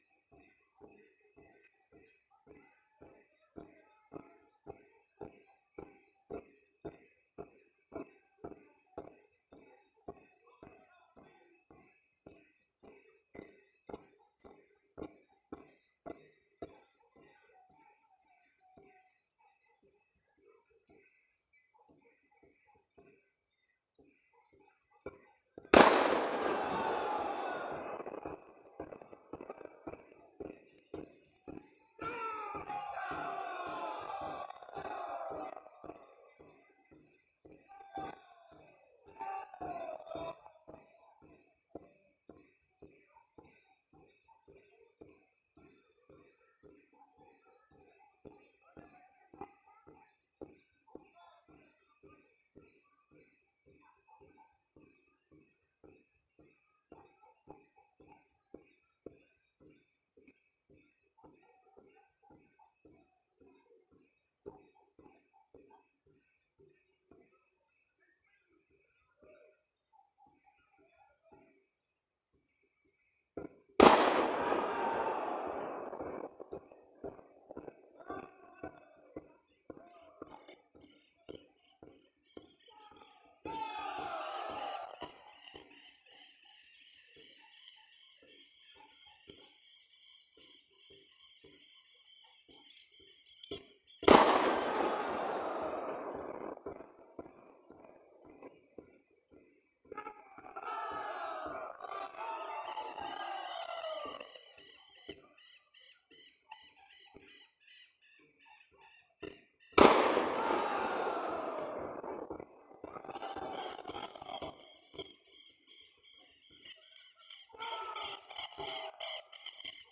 Regularmente, cuando se reúne con su grupo —casi siempre de madrugada— se escuchan disparos. Y en algunas ocasiones, gritos.